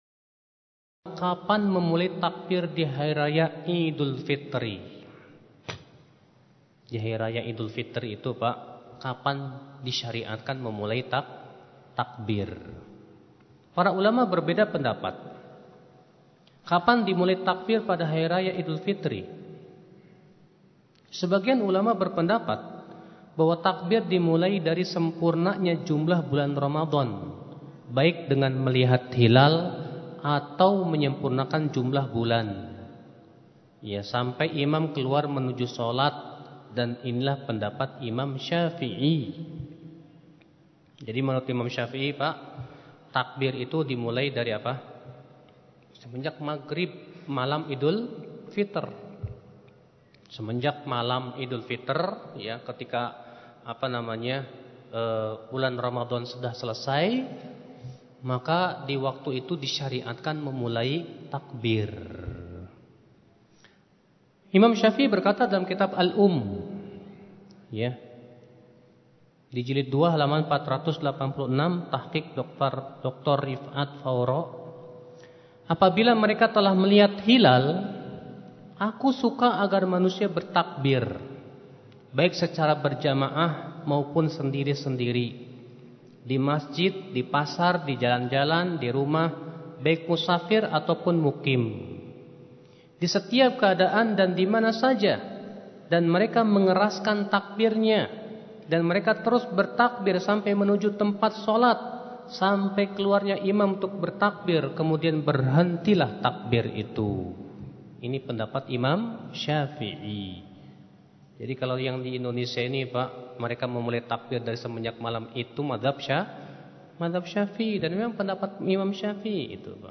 Dari Kajian Renungan Takbir, 10 April 2016 di Masjid Al Kautsar, Polda Metro Jaya, Jakarta.